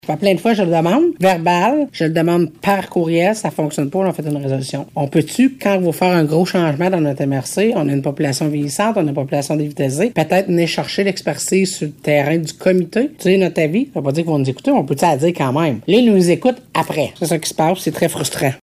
La préfète de la Vallée-de-la-Gatineau affirme avoir fait la demande que le comité soit entendu à plusieurs reprises, on l’écoute :